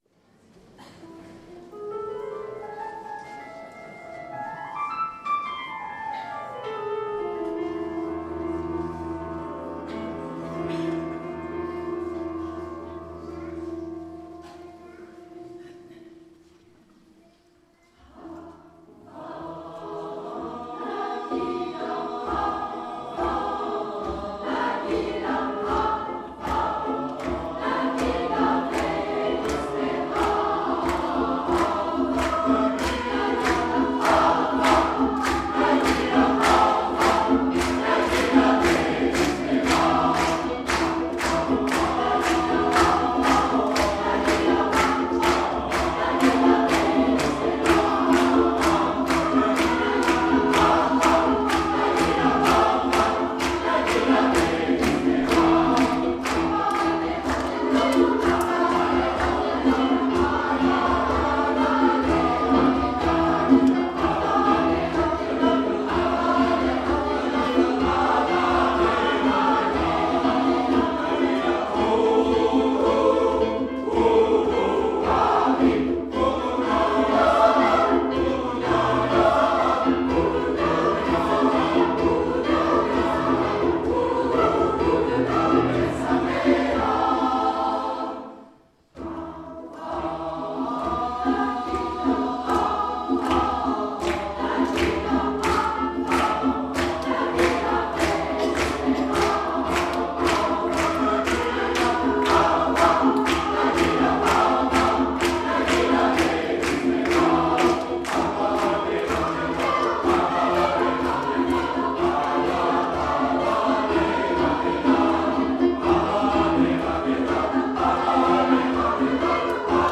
Extraits audios du concert du dimanche 12 mai 2013
Chorale HARMONIA de Saint Pavace Hava Naghila Traditionnel juif Harmonia St Pavace hava naghila
17h00 : concert des trois chorales à l'Eglise Saint Bertrand du Mans :